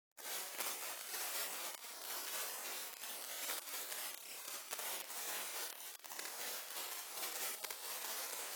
Sound-Objects
Electroacoustic
Experimental
noise-01.wav